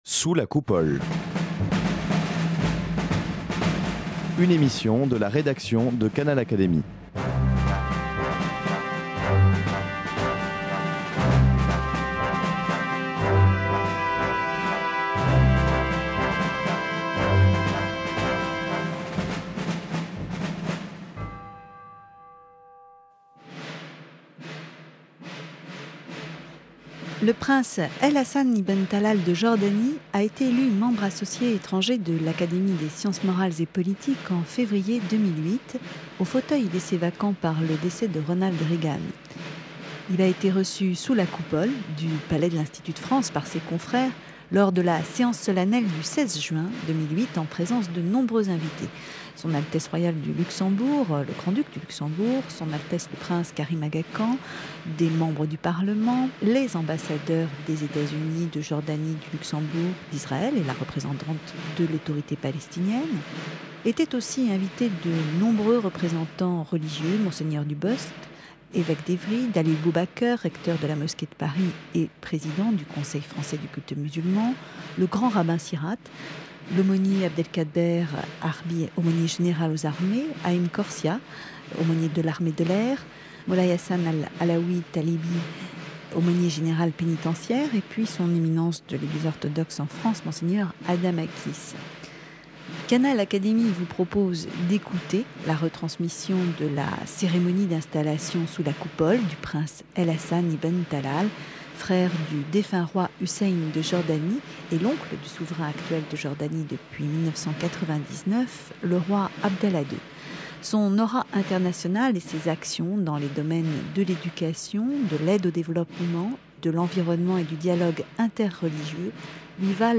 Séance solennelle d’installation du Prince El Hassan bin Talal, membre associé étranger de l’Académie des sciences morales et politiques.
Ecoutez sur Canal Académie la retransmission de cette séance qui s’est déroulée le 16 juin sous la Coupole de l’Institut de France.